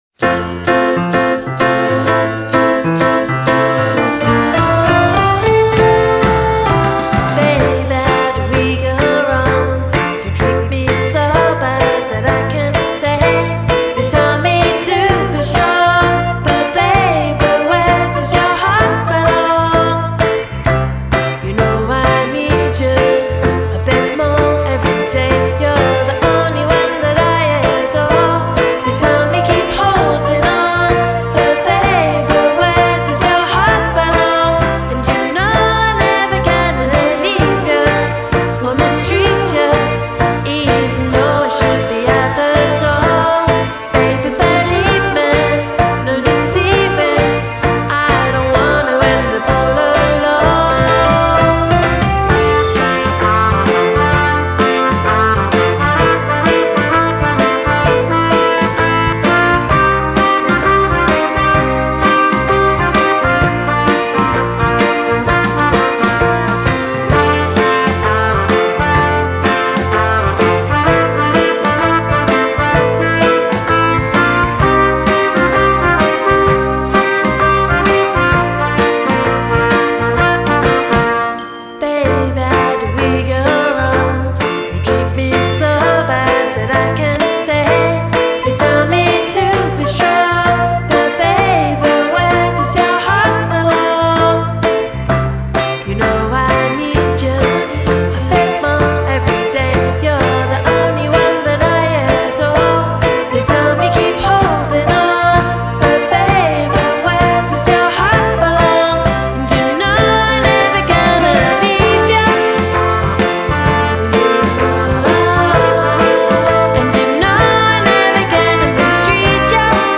Genre: Indie Pop / Twee